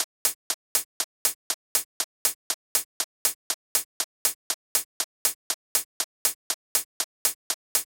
29 Hihat.wav